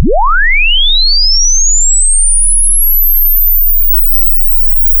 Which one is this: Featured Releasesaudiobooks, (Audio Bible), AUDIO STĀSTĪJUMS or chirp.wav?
chirp.wav